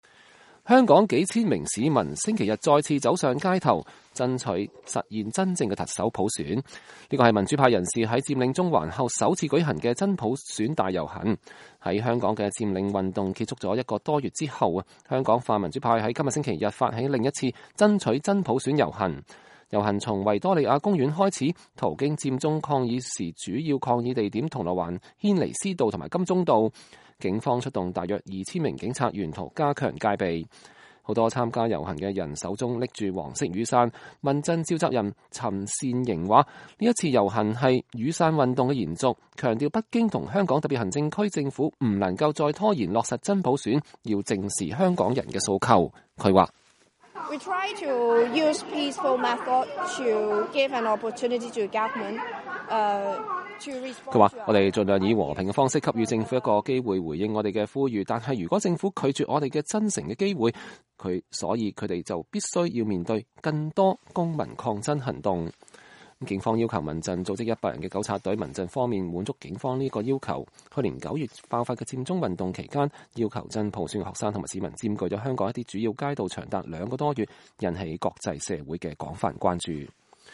2015-02-01 美國之音視頻新聞: 香港民陣發起爭普選遊行